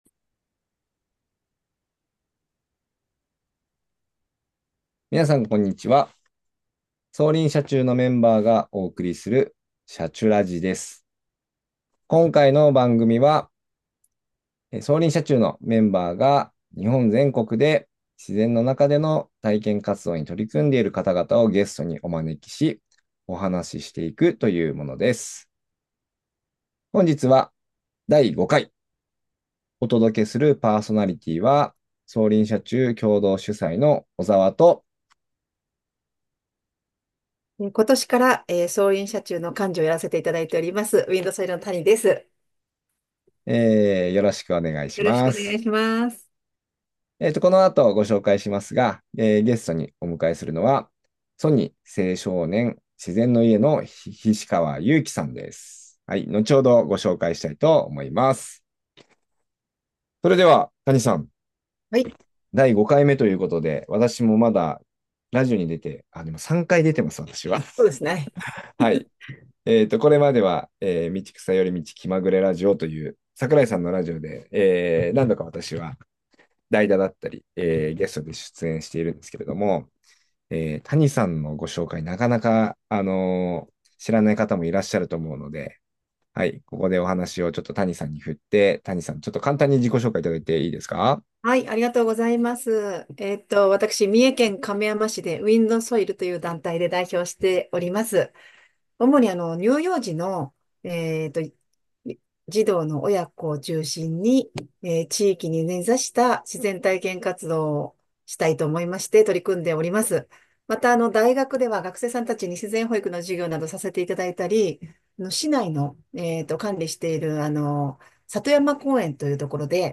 「シャチュラジ」この番組は、走林社中のメンバーが日本全国で自然の中での体験活動に取り組んでいる方々をゲストにお招きし、お話ししていくラジオです。